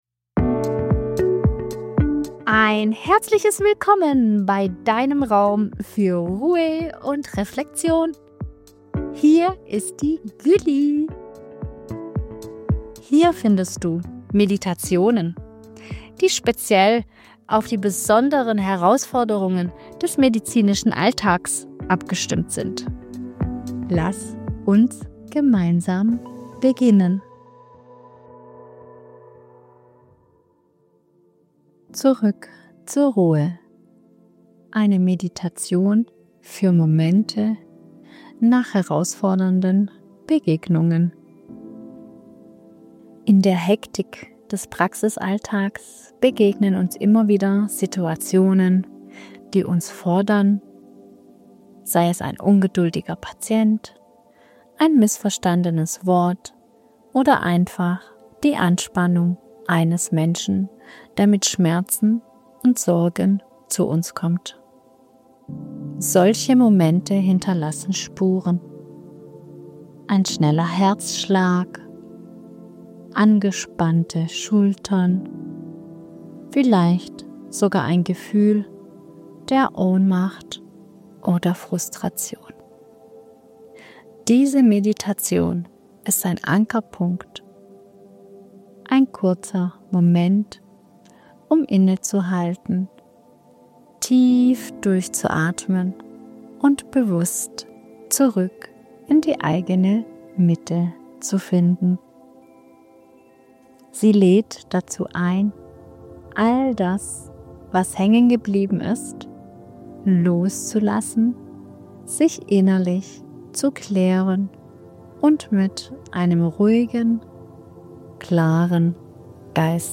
In dieser Episode leite ich Dich durch eine beruhigende